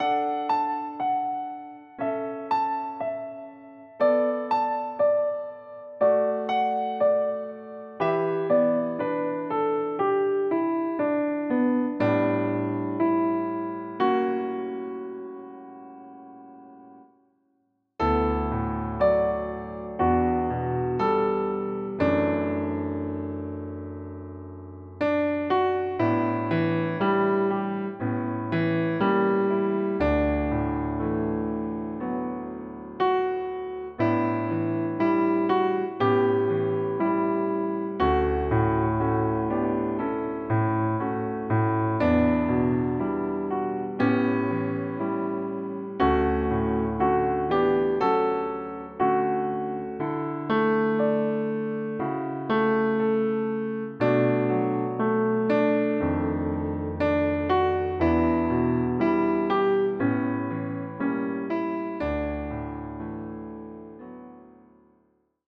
Besetzung: Klavier
wunderschöne Jazzballade